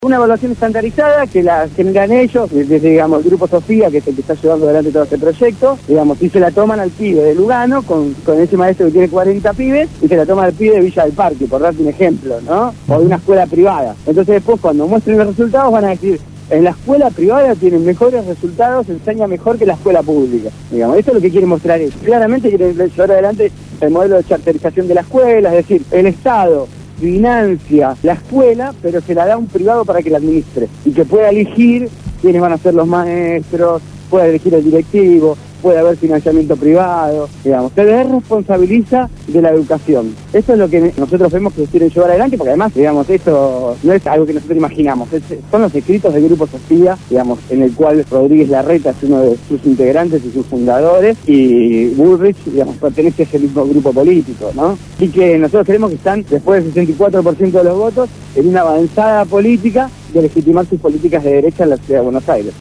en el programa Desde el Barrio por Radio Gráfica FM 89.3